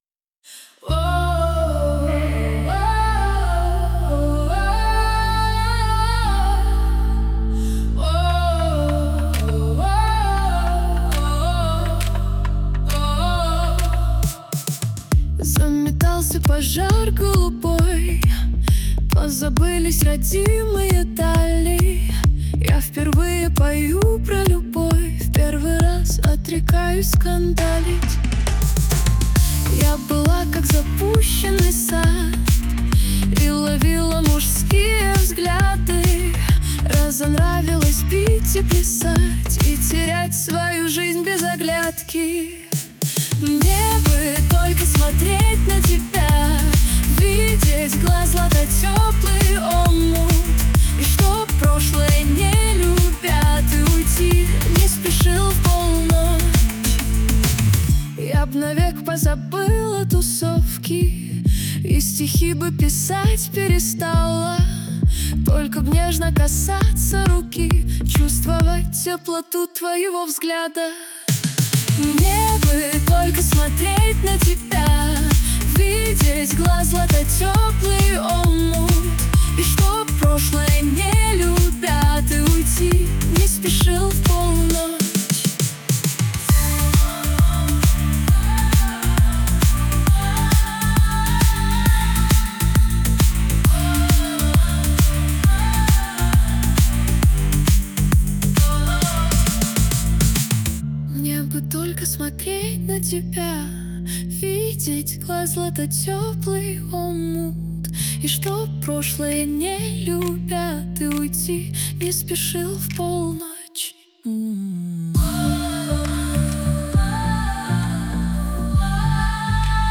RUS, Romantic, Lyric, Dance, Pop, Disco | 16.03.2025 11:02